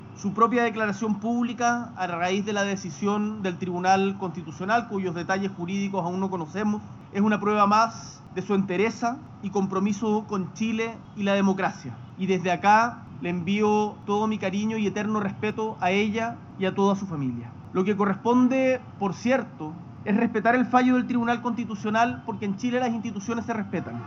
En el marco de su último día de gira en la India, el presidente Gabriel Boric se refirió al fallo y destacó la trayectoria de Allende, señalando que su rol en el Congreso fue fundamental para la democracia y que su contribución durante tres décadas ha sido invaluable.